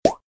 pop_3.ogg